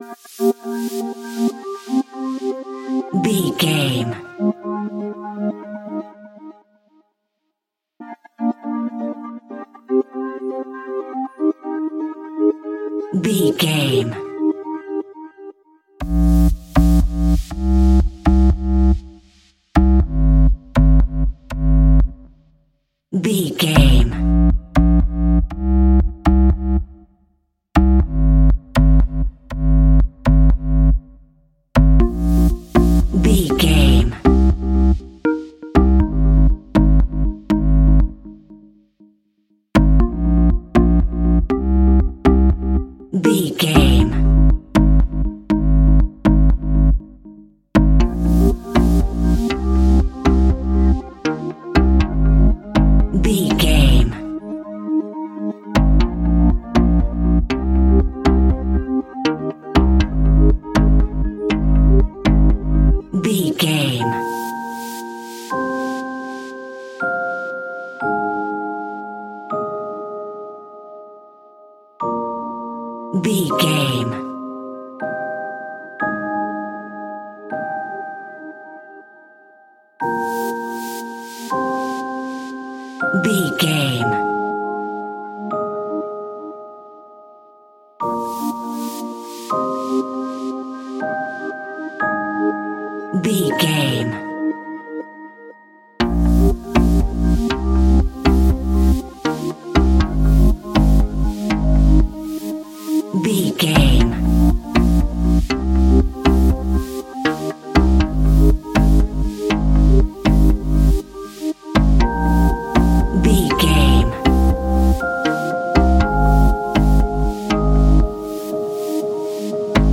Aeolian/Minor
synthesiser
sleigh bells